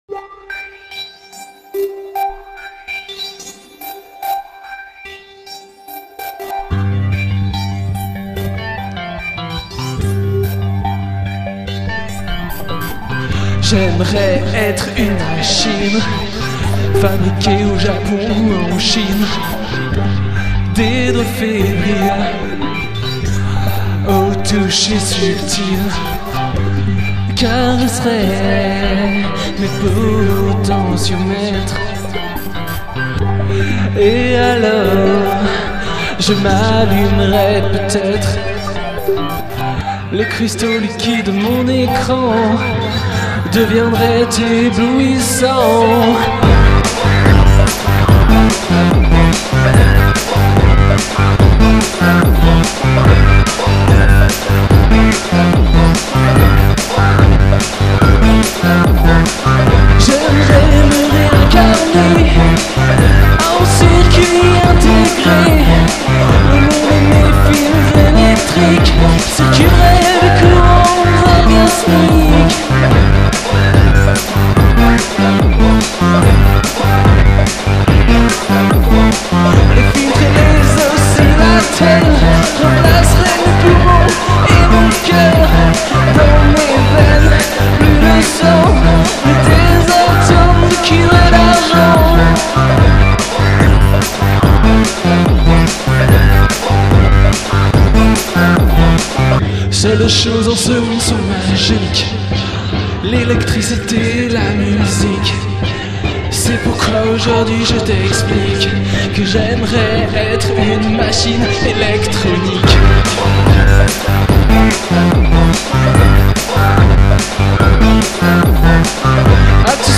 Le climat oscille entre pop psychédélique